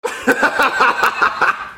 jeffy laugh sml Meme Sound Effect
Category: Reactions Soundboard
jeffy laugh sml.mp3